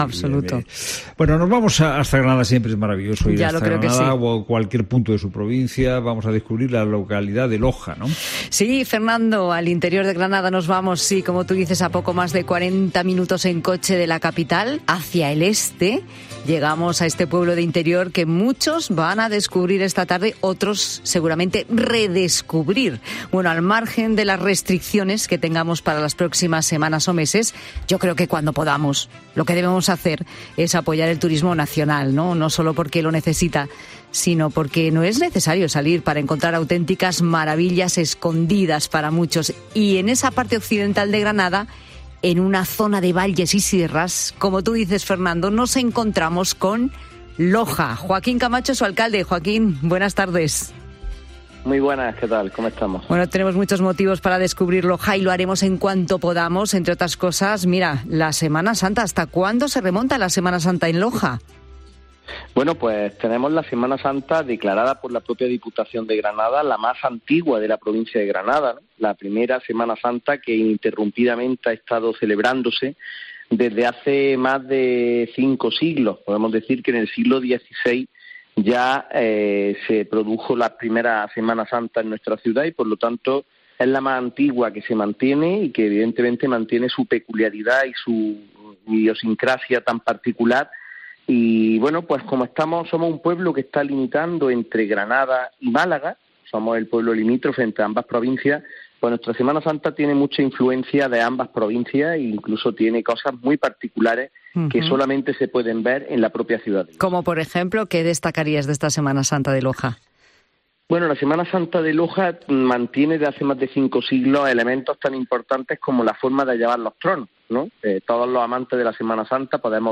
El alcalde de Loja en 'La Tarde de COPE' ensalzando su Semana Santa
AUDIO: Escucha la entrevista a Joaquín Camacho en el programa de Pilar Cisneros y Fernando de Haro